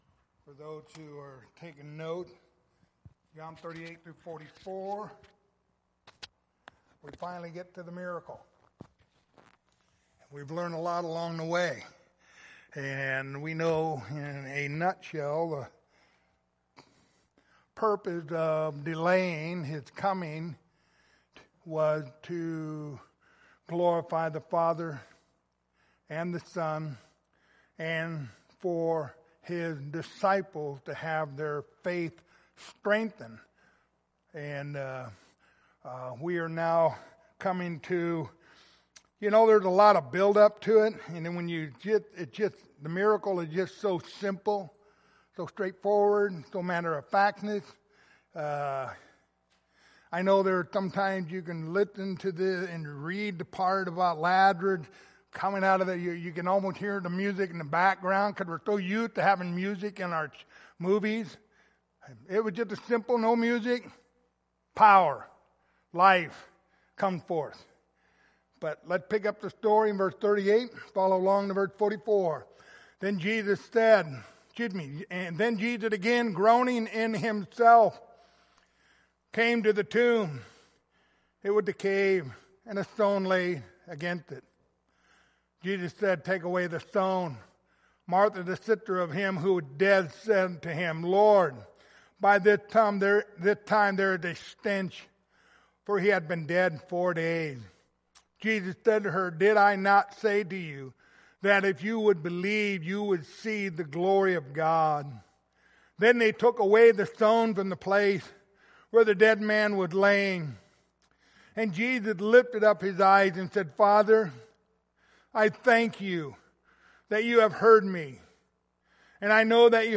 Passage: John 11:38-44 Service Type: Wednesday Evening